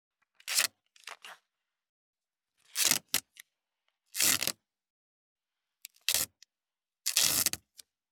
164.ガムテープ【無料効果音】
効果音